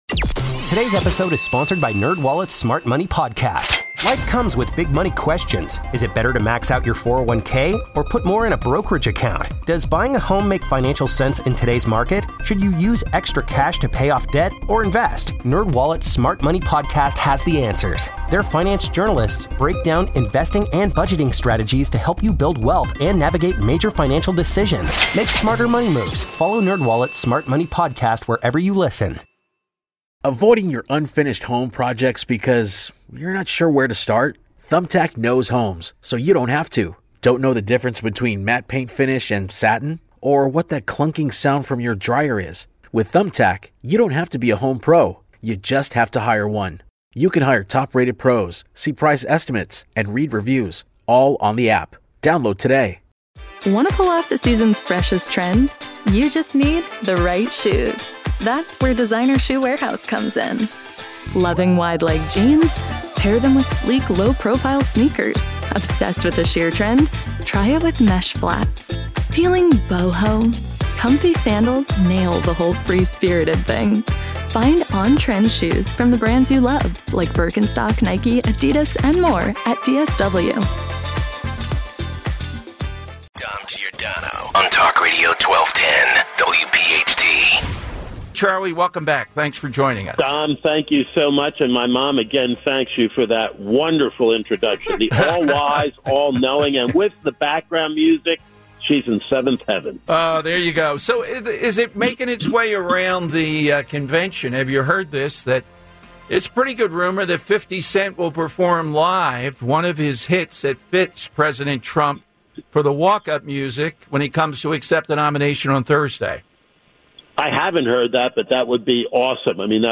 Live from Milwaukee at the RNC